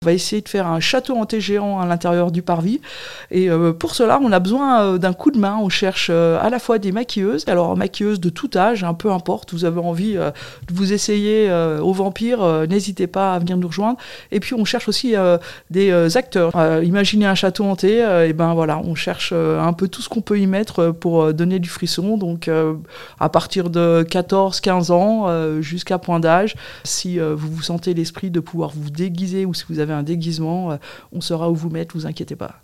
Catherine Plewinski, la Présidente du comité des fêtes nous parle de cette recherche de figurants à Cluses :